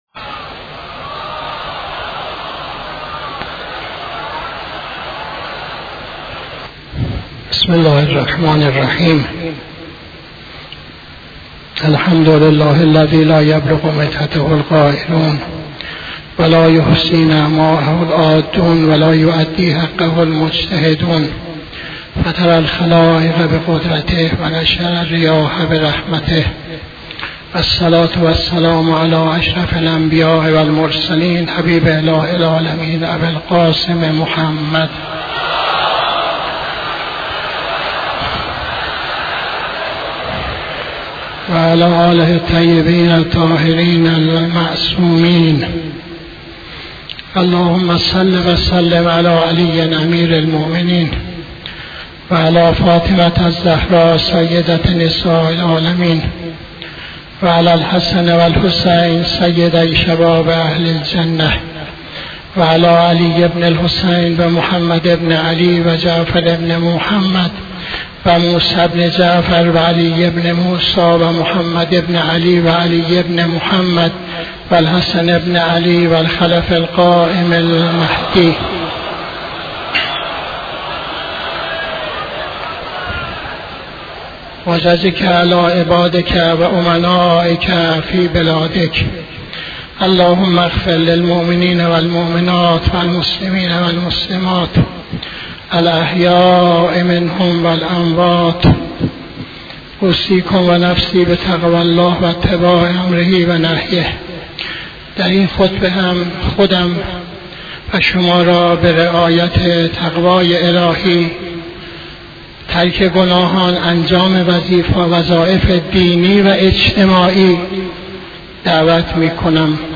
خطبه دوم نماز جمعه 20-09-83